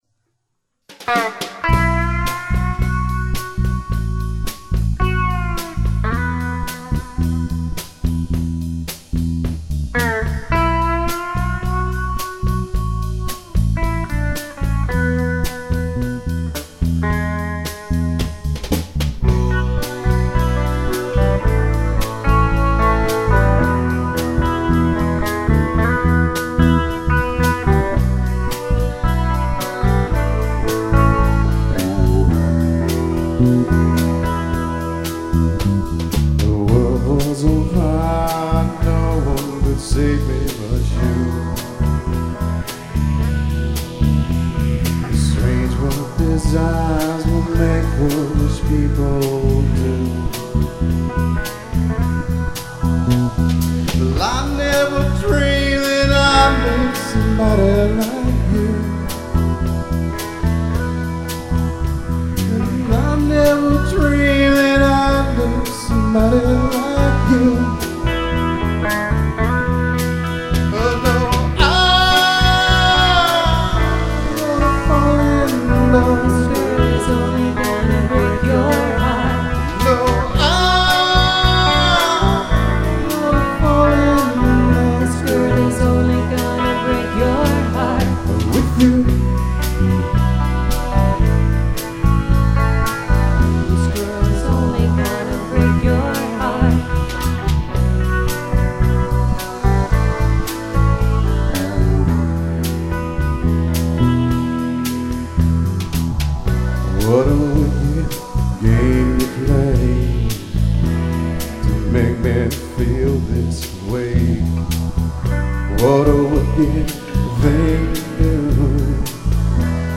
first studio album
The voice and that guitar are something very special.